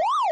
siren_fast.wav